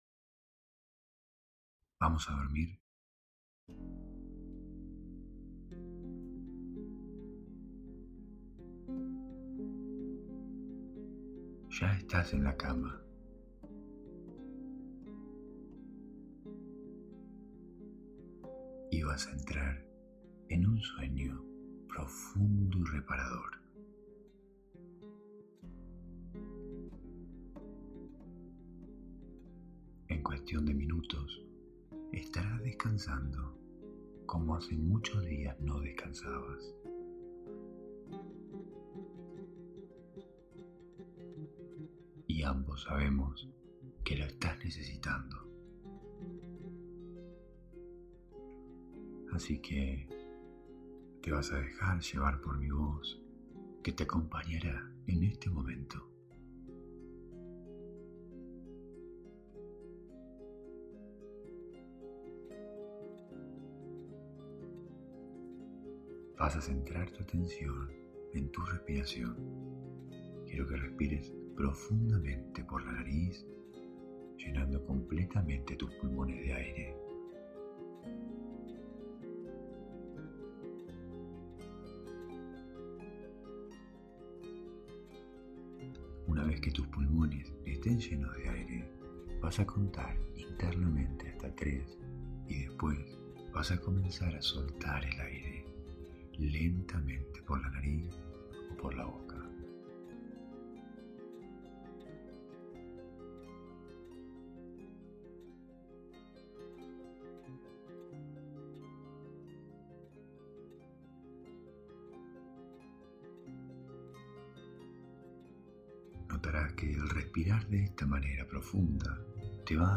Hipnosis guiada para dormir profundamente.